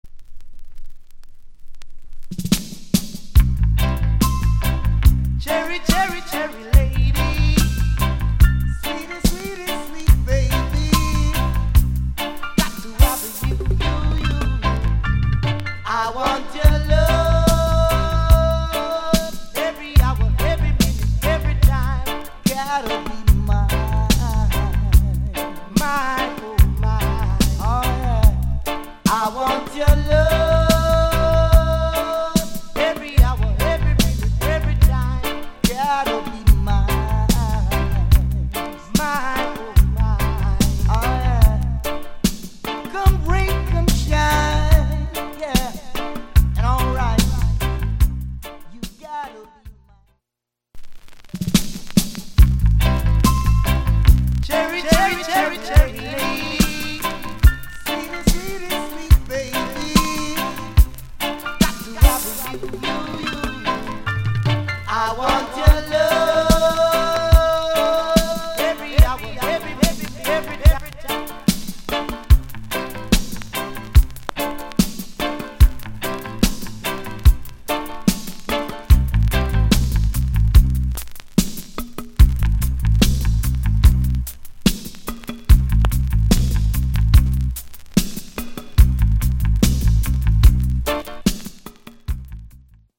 * Mid 80's Mellow Vocal Duo/Tuff Riddim Good Tune